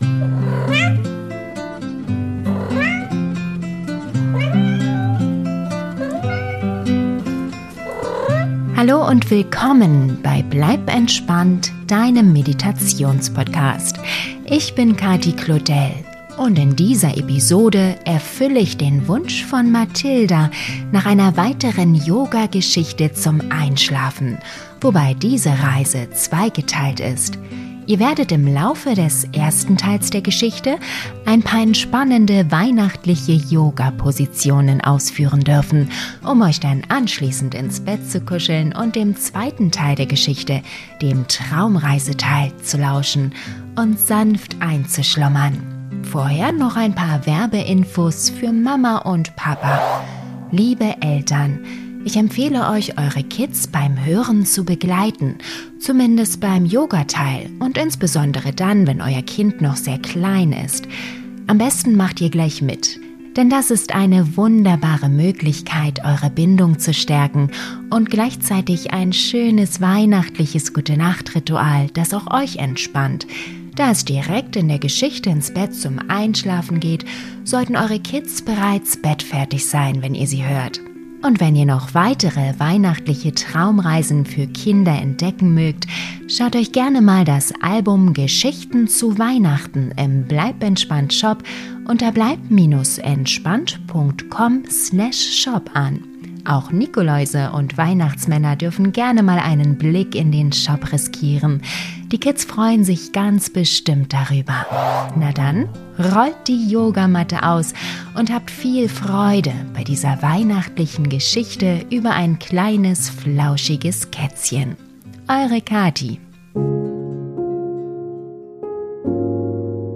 Diese zweigeteilte Kinderyoga-Geschichte ist die ideale Gute-Nacht-Kombination, um kleine Katzenfans sanft in den Schlaf zu begleiten: Im ersten Teil darf dein Kind ein paar entspannende weihnachtliche Yoga-Positionen ausführen, um sich dann anschließend beim reinen Traumreiseteil ins Bett zu kuscheln und sanft einzuschlummern. Vom Katzenbuckel im Wohnzimmer geht die magische Reise mit einem Sprung auf den Schlitten des Weihnachtsmannes direkt zum Nordpol!